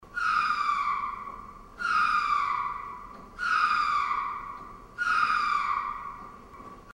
LEMUR
Lemur.mp3